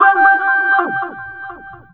VOX FX 2  -R.wav